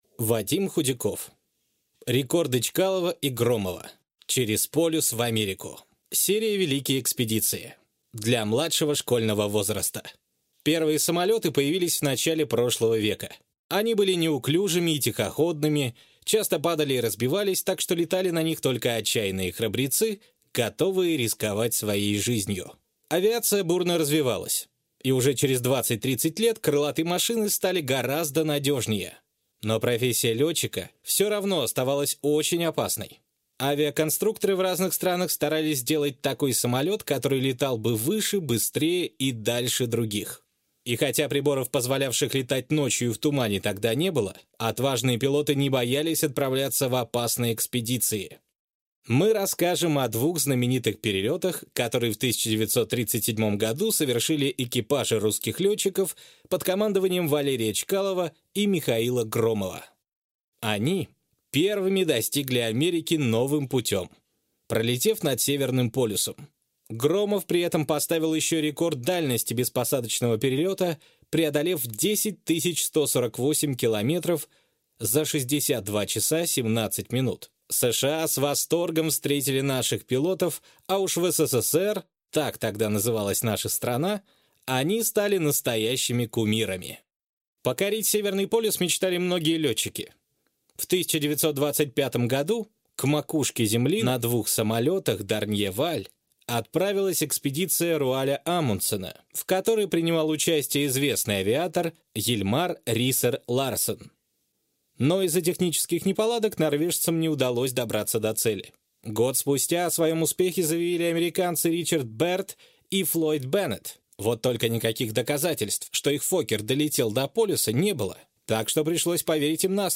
Аудиокнига Рекорды Чкалова и Громова. Через полюс – в Америку | Библиотека аудиокниг